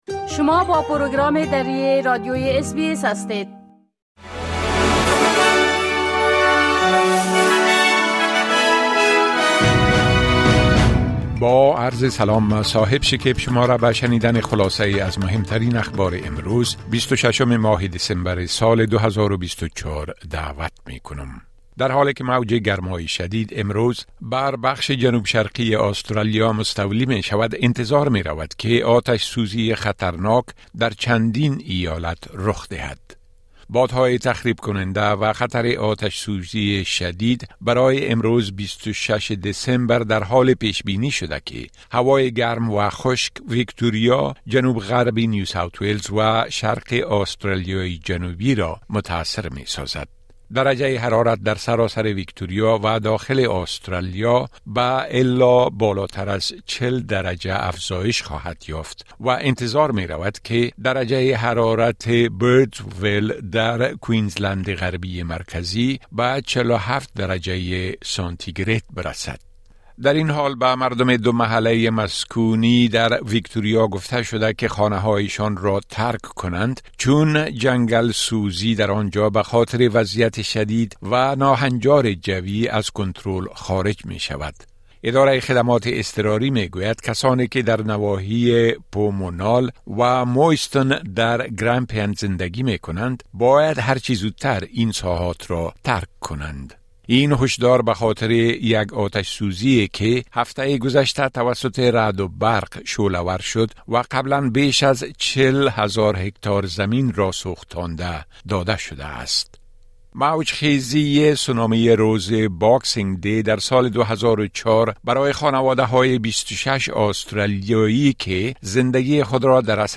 خلاصۀ مهمترين اخبار روز از بخش درى راديوى اس بى اس
10 am News Update Source: SBS / SBS Filipino